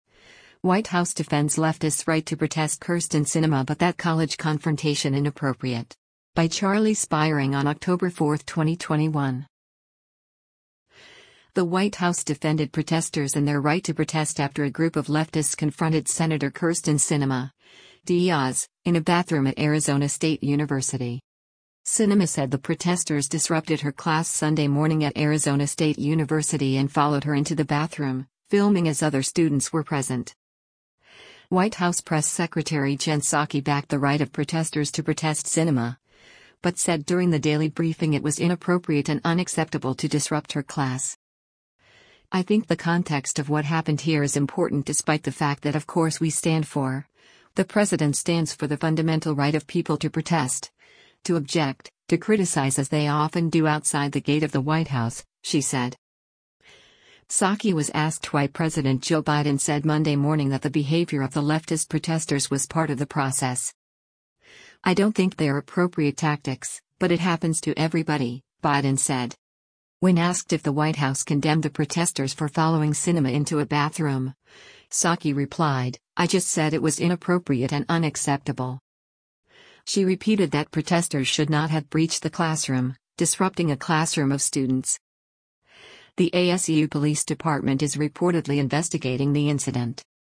Daily White House Briefing Held By Secretary Psaki
White House press secretary Jen Psaki backed the right of protesters to protest Sinema, but said during the daily briefing it was “inappropriate and unacceptable” to disrupt her class.